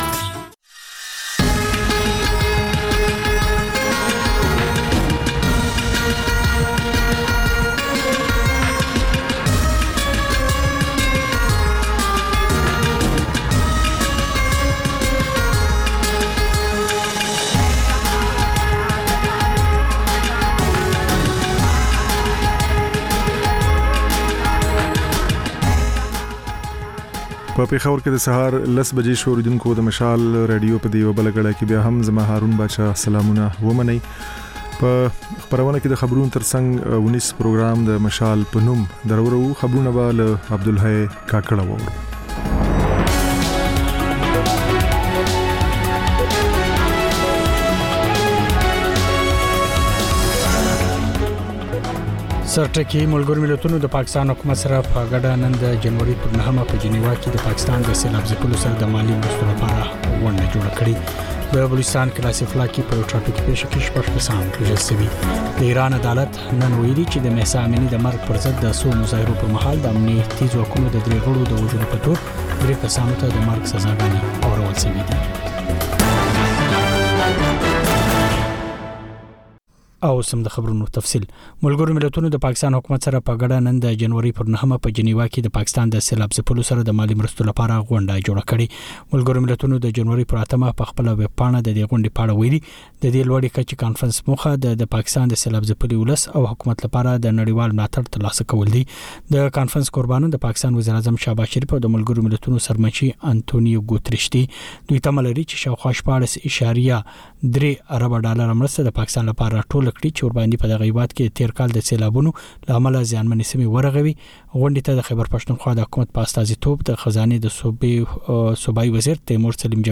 د مشال راډیو لومړۍ ماسپښينۍ خپرونه. په دې خپرونه کې تر خبرونو وروسته بېلا بېل رپورټونه، شننې، مرکې خپرېږي. په دې ګړۍ کې اوونیزه خپرونه هم خپرېږي.